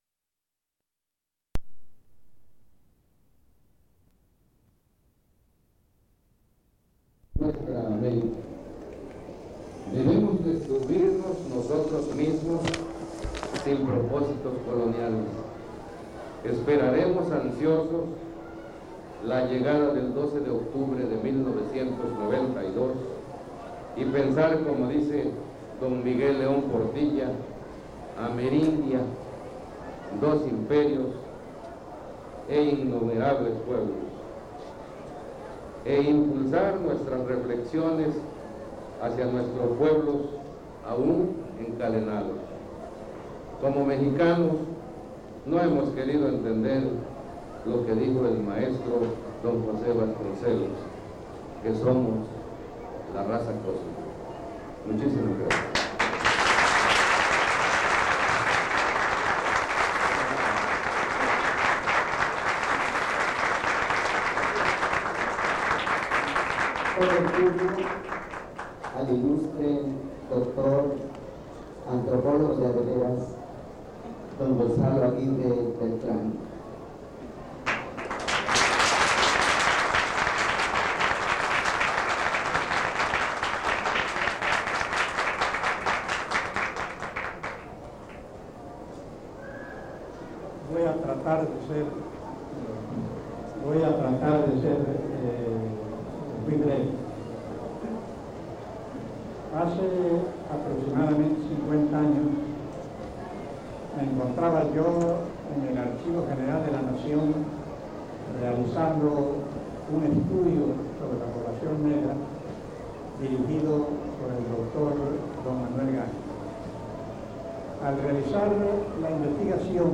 Ponencia
Santiago Tuxtla, Veracruz, Mexico